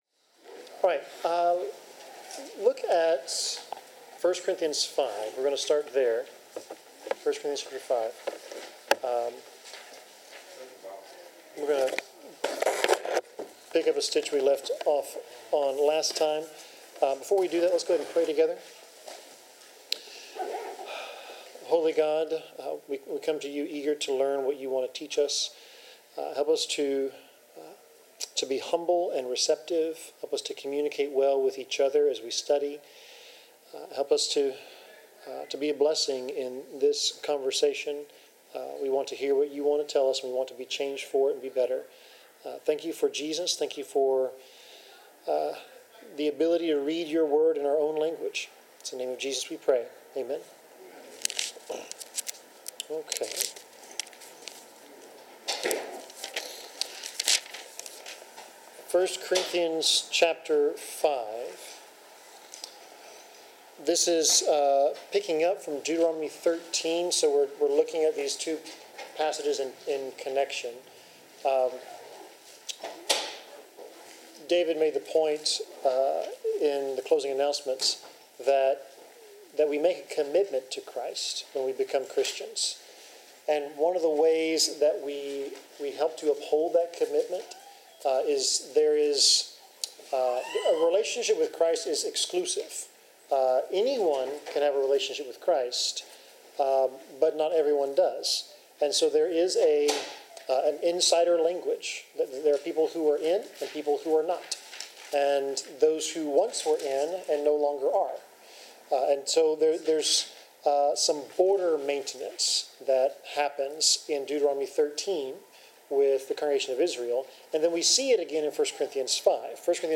Bible class: Deuteronomy 13-14
Service Type: Bible Class Topics: Clean , Discipline , Faith , Fellowship , Holiness , Obedience , Tithing , Unclean